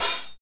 Index of /90_sSampleCDs/Northstar - Drumscapes Roland/DRM_Medium Rock/KIT_M_R Kit 2 x
HAT M R LH0I.wav